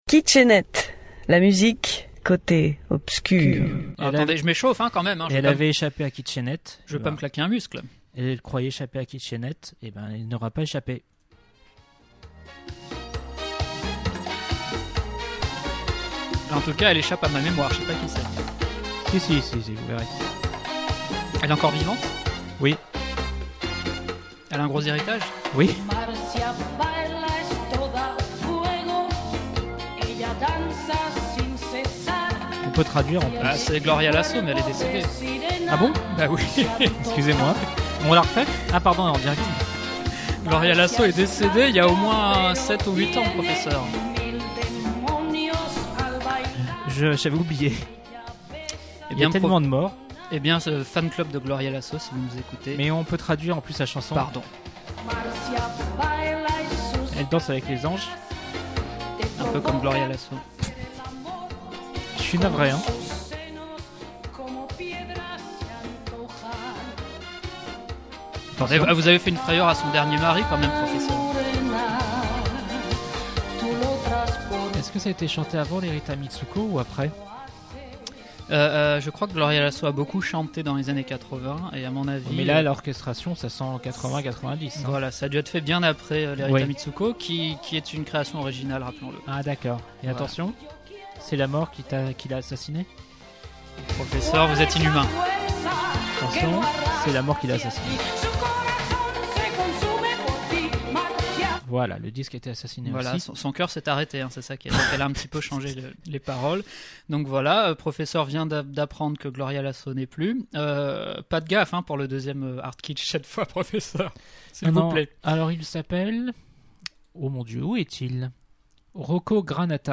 Chaque animateur de « Kitsch et Net » fait découvrir en live à ses petits camarades des extraits musicaux dits « HARDkitschs » (voir ici la définition), qui ne seront pas diffusés en entier…
Savourez à volonté tous ces happenings pleins de surprises, de délires et surtout de gros éclats de rire, et retrouvez aussi des informations sur les chanteurs diffusés…
Attention à vos oreilles…